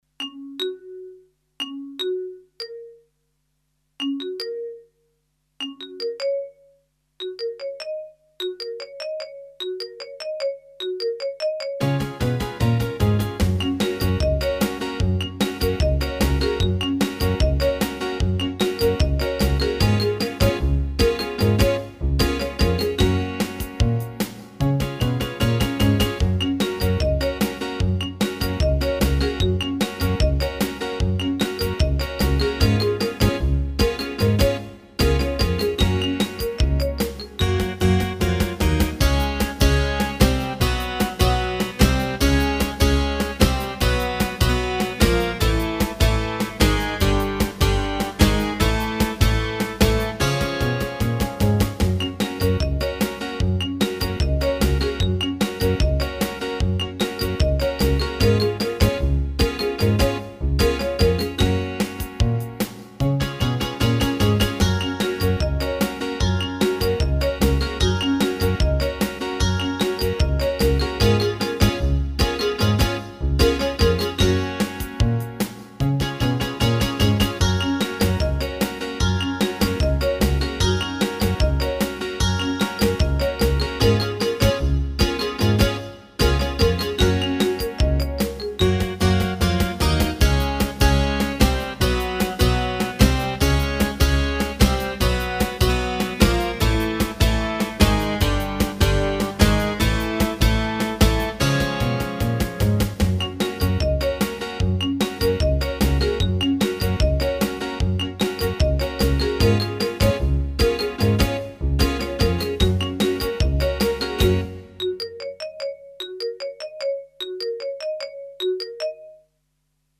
Instrumental
Selected sound: Marimba
Selected sound: Acoustic Bass
Selected sound: Acoustic Grand Piano
Selected sound: Acoustic Guitar (steel)
Selected sound: Clean Guitar
Selected sound: Drumset